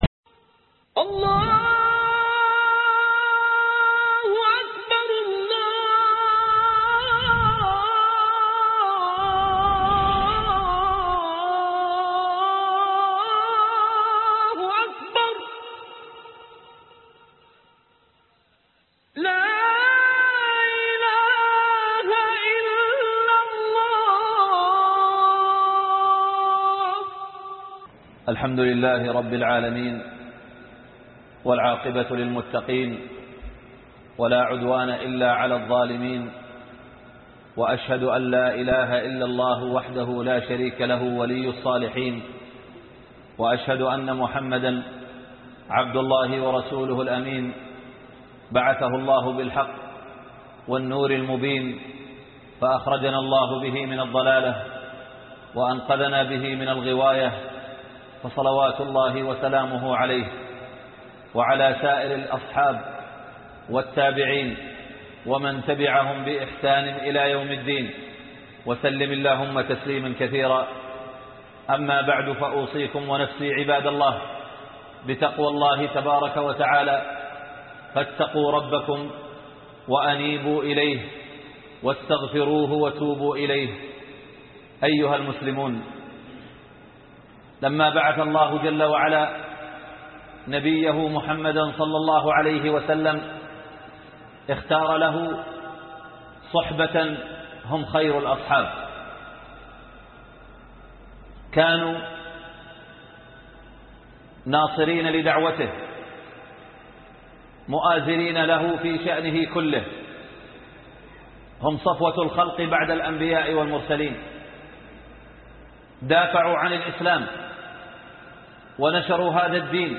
ثانى اثنين ( خطب الجمعة